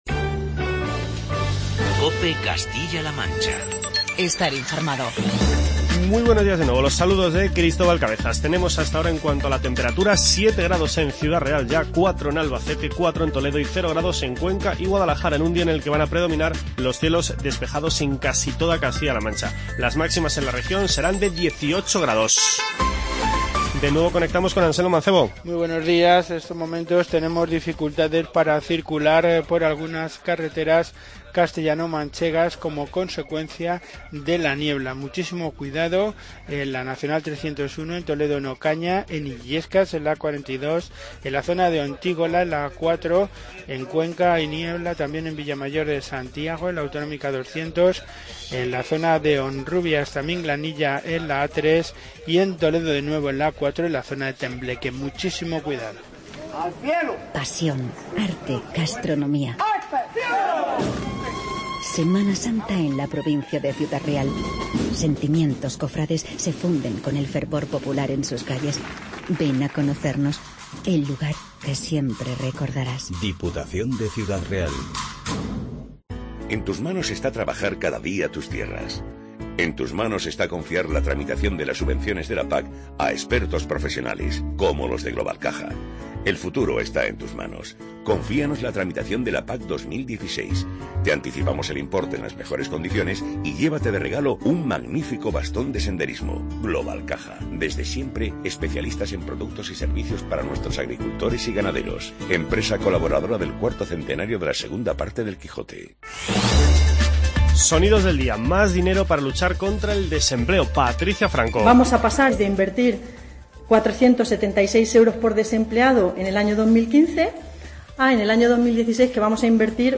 Informativo regional
Hoy en nuestras "Voces de los Protagonistas" destacamos las palabras de Patricia Franco, Lorenzo Robisco, Rafael Esteban y Ana Guarinos.